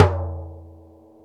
TOM XC.TOM05.wav